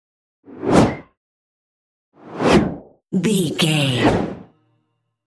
Whoosh fast bright x3
Sound Effects
Fast
bright
futuristic
whoosh